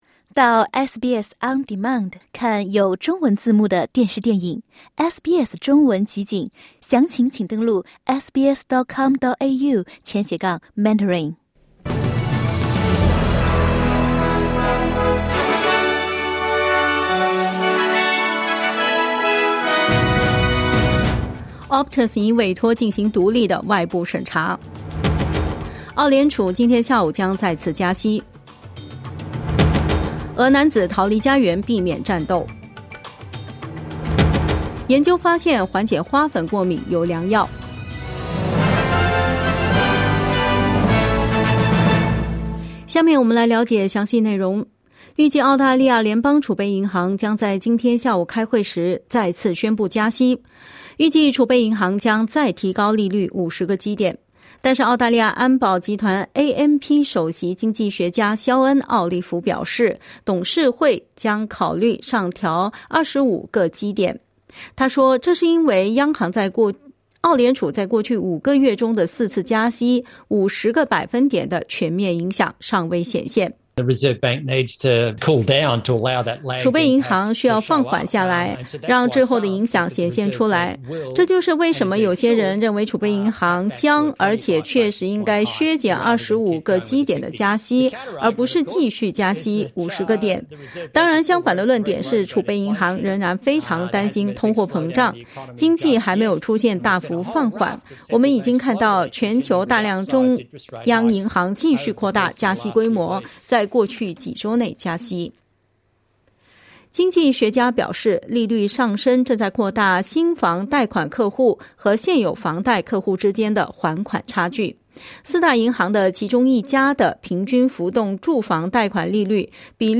SBS早新闻 (2022年10月4日)